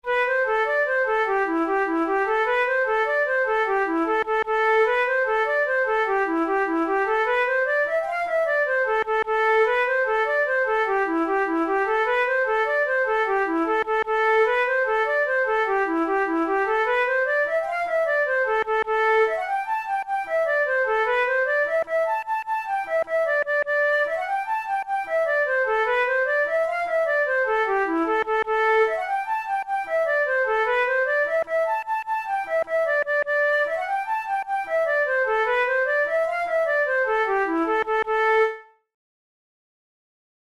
InstrumentationFlute solo
KeyA minor
Time signature6/8
Tempo100 BPM
Jigs, Traditional/Folk
Traditional Irish jig